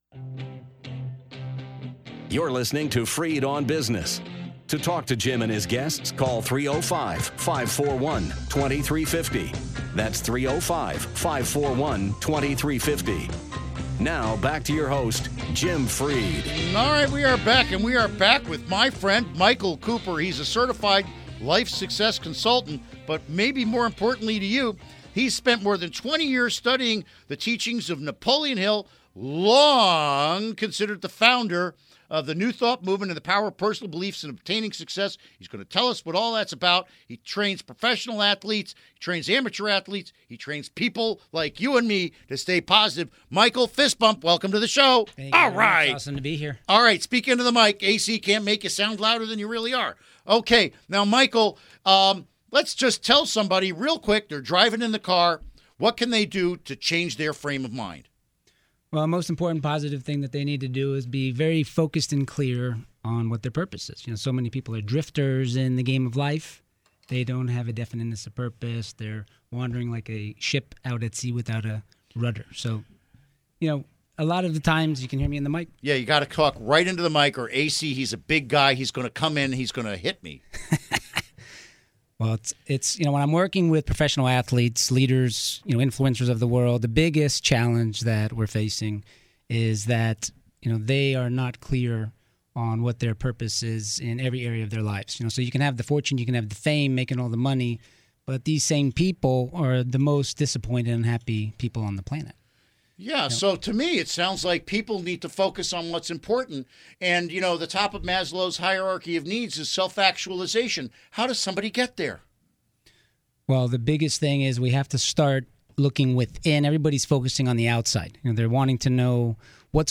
Interview Segment Episode 362: 03-31-16 Download Now!